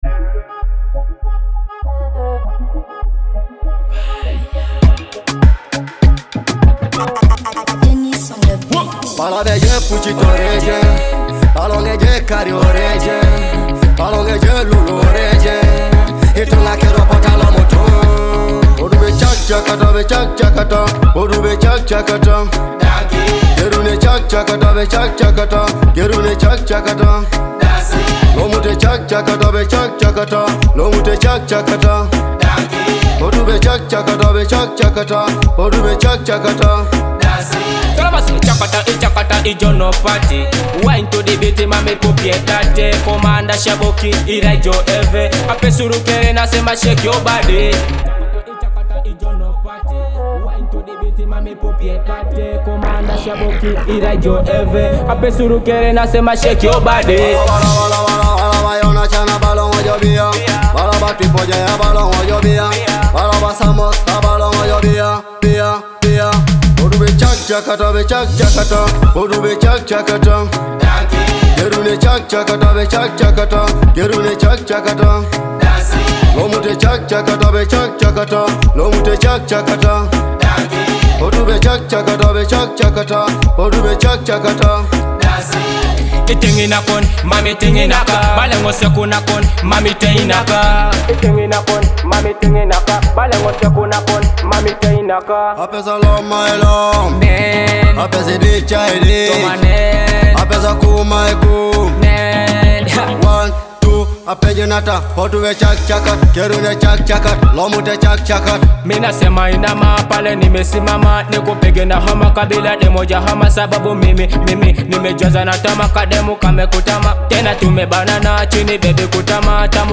Teso dancehall beats
the ultimate Teso dancehall party hit!